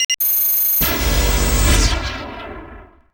vehicleRelocate.wav